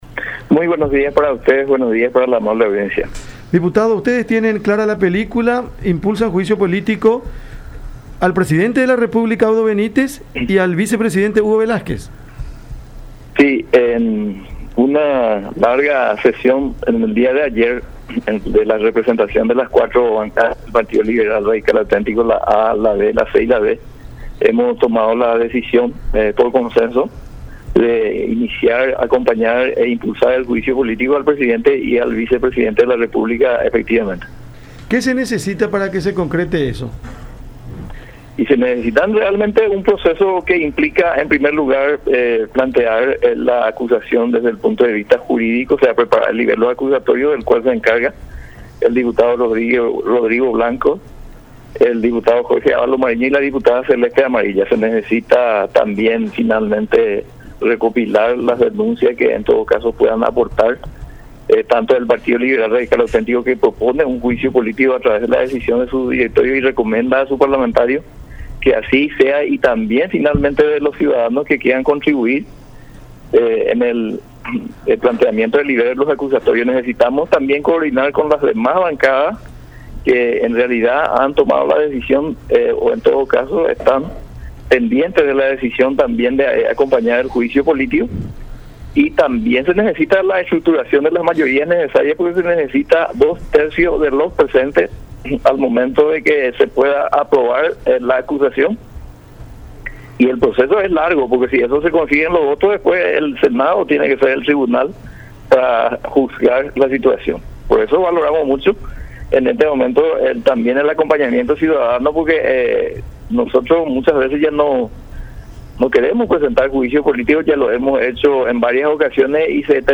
“Tras una larga sesión, las 4 bancadas tomaron la decisión de acompañar e impulsar el juicio político tanto al presidente como al vicepresidente de la República”, señaló Celso Kennedy, líder de una de las bancadas del PLRA, en contacto con La Unión.